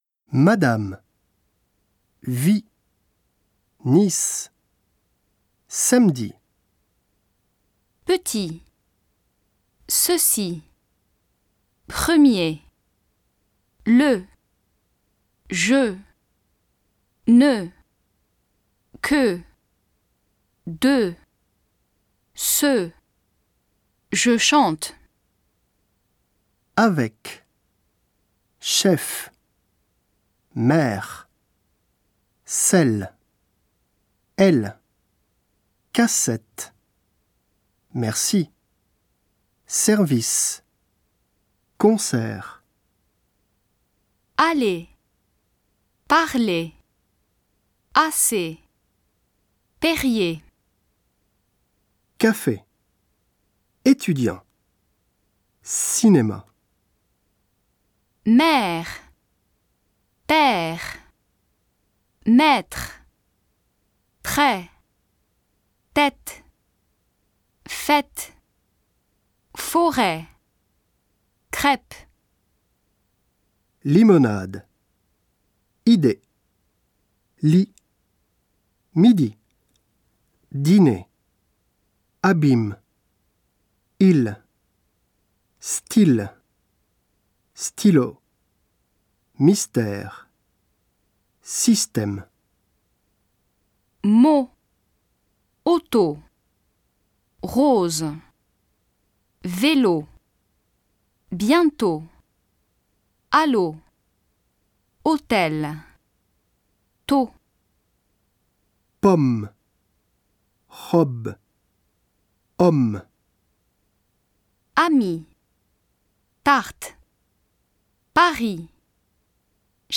3　綴り字と発音
1)母音
le　je　ne　que　de　ce　je chante単語の最後のeは[ə]と発音される。
é[e]café　étudiant　cinéma唇を横に引く感じで狭い「エ」、鋭い音。
sur　nature　jus　culture  「イ」の口からずーっとすぼめていって前に唇を突き出して「ユ」。緊張した鋭い音。